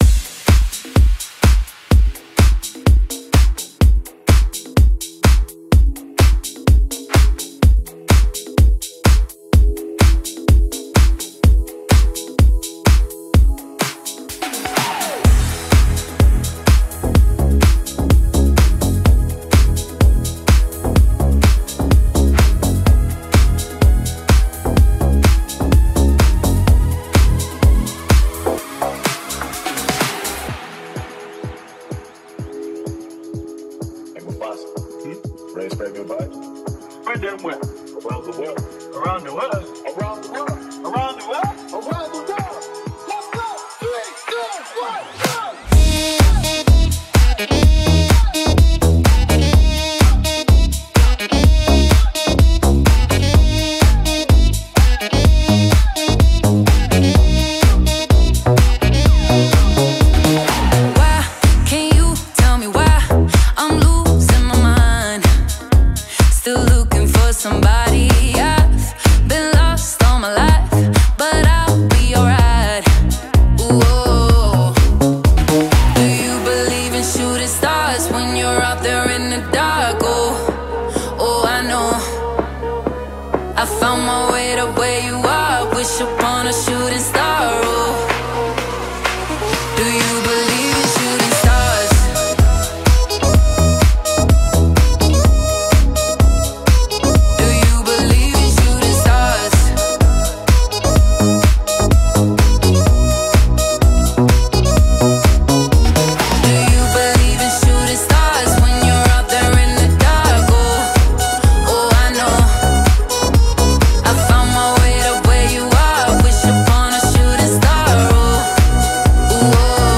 Os presentamos una versión extended de este tema